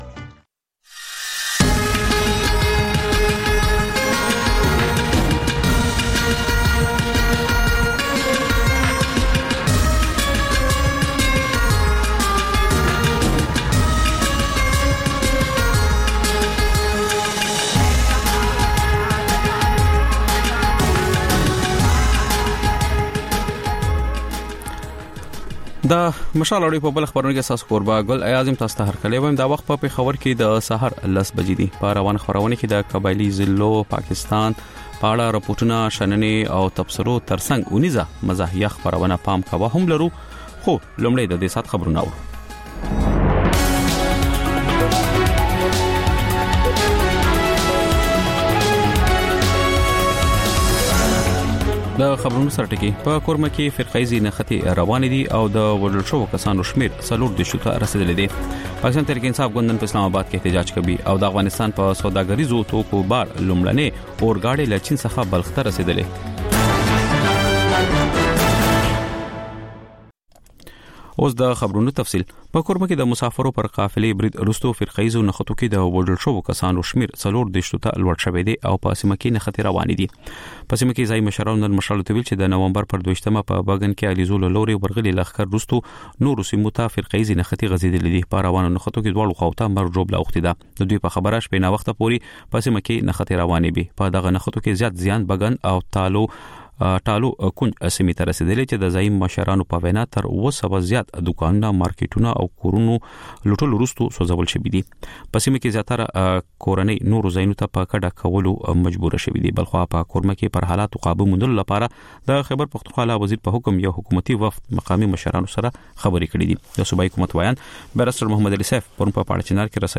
په دې خپرونه کې تر خبرونو وروسته بېلا بېل رپورټونه، شننې او تبصرې اورېدای شئ. د خپرونې په وروستۍ نیمايي کې اکثر یوه اوونیزه خپرونه خپرېږي.